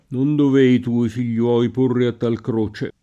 figliolo [fil’l’0lo] s. m. — sempre con F- maiusc. come nome della seconda persona della Trinità; e spesso anche in figliolo (o Figliolo) di Dio e altri titoli di Gesù Cristo — anche figliuolo [fil’l’U0lo], con spesso una sfumatura di solennità: diletti figliuoli…